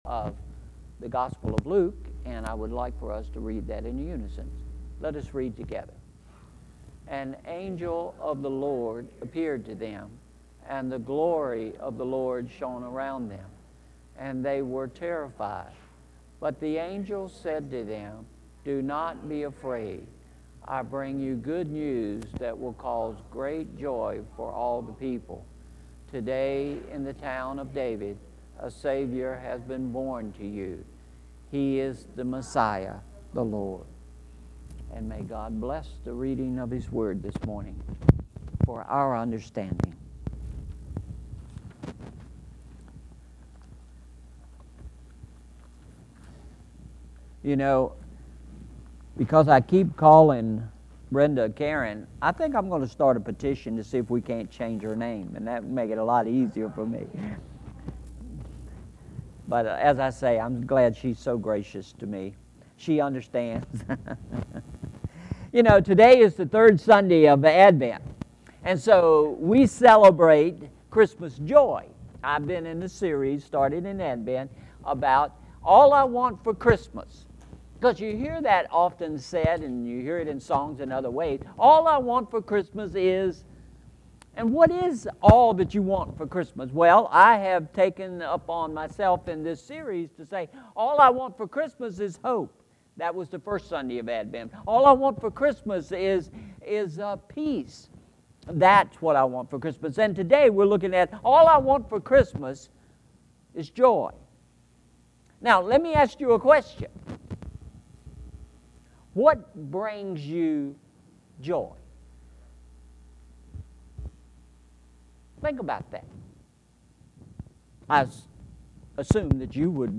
Sermon Series: All I Want for Christmas – Part 3